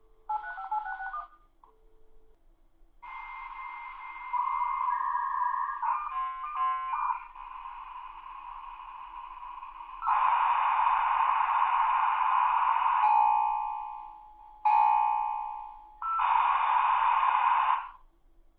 modem-connect.mp3